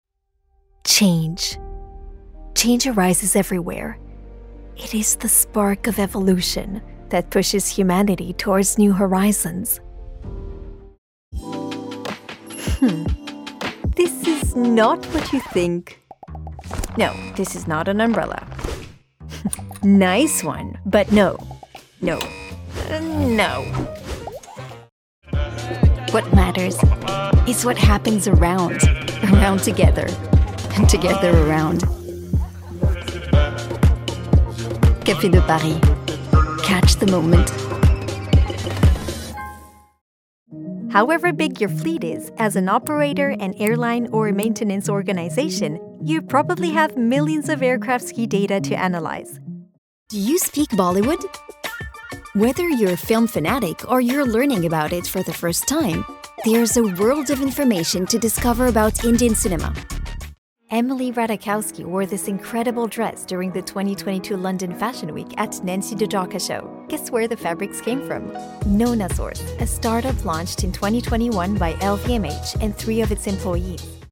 Comédienne voix off bilingue: Un sourire dans une voix…
Ayant grandi en France avec un père américain, j’enregistre en français neutre ainsi qu’en anglais, avec une subtile touche européenne.